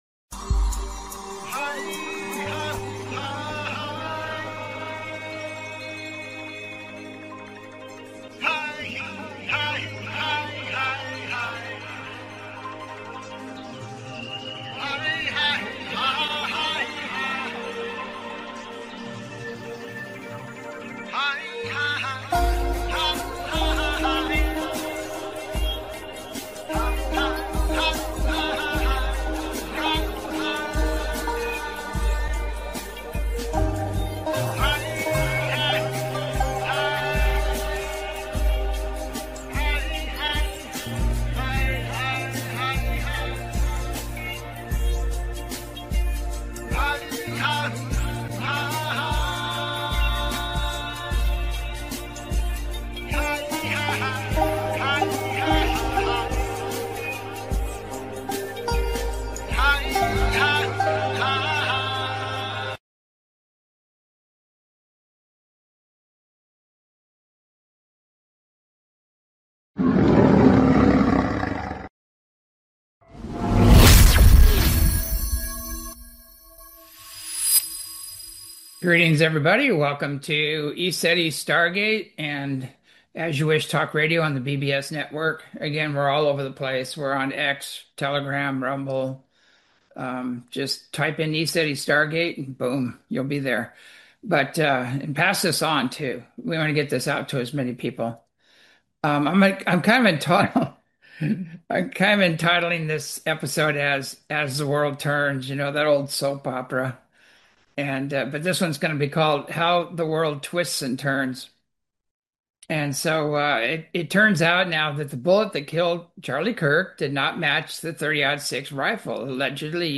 Talk Show Episode, Audio Podcast, As You Wish Talk Radio and Charle Kirk, Epstien, Ba'al, UFO/UAP on , show guests , about Charle Kirk,Epstien,Ba'al,UFO/UAP,Geopolitics,Geoengineering,Path to Sovereignty,political assassinations,Media Narrative,Global Control,Spiritual warfare, categorized as Earth & Space,Entertainment,Paranormal,UFOs,Physics & Metaphysics,Politics & Government,Society and Culture,Spiritual,Theory & Conspiracy